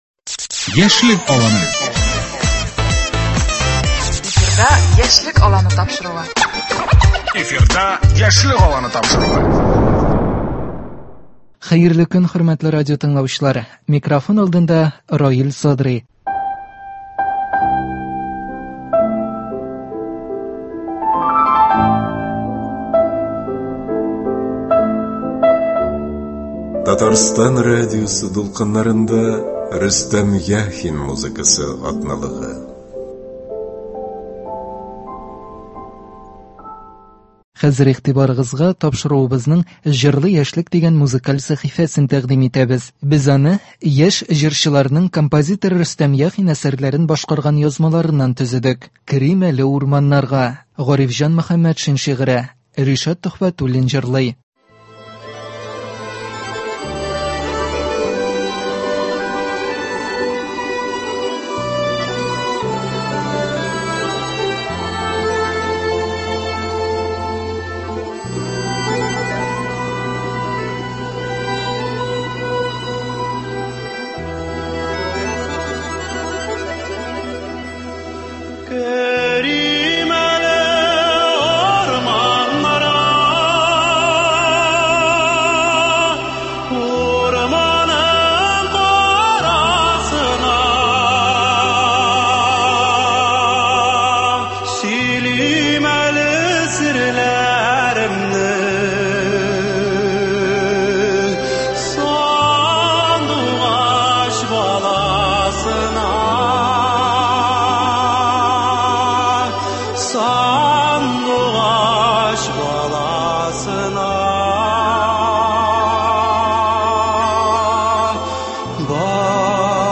яшь җырчыларның